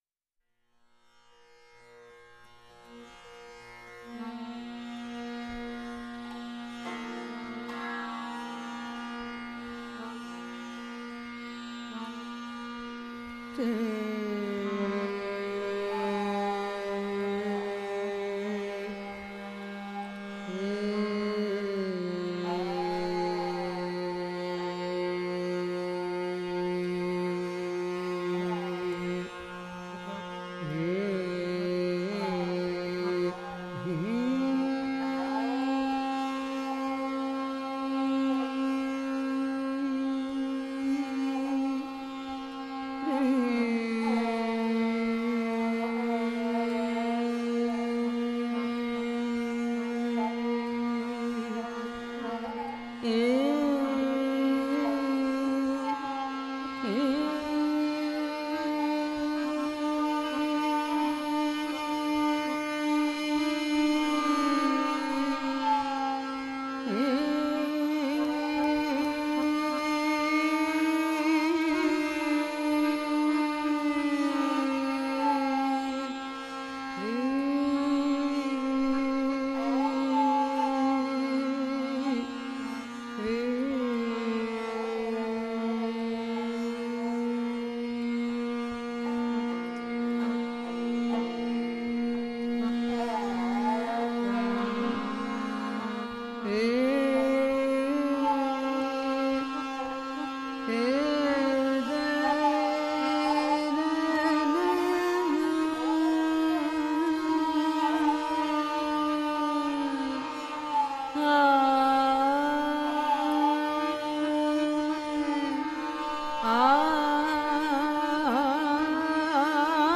sāraṅgī
harmonium
tablā
at Music Room Studios, Bombay